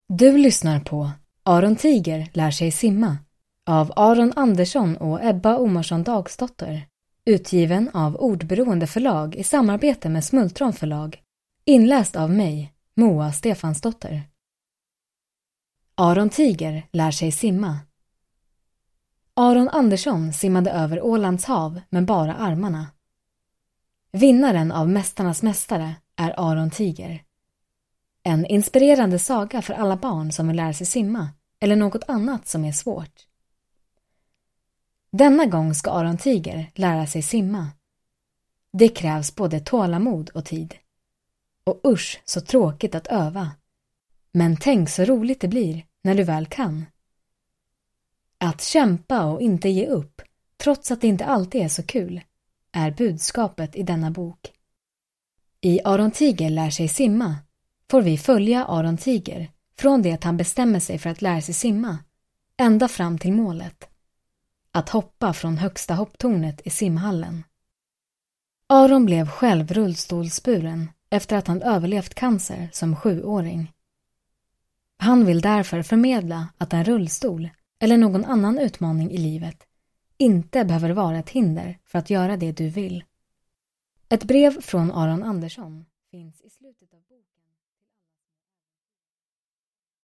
Aron Tiger lär sig simma – Ljudbok – Laddas ner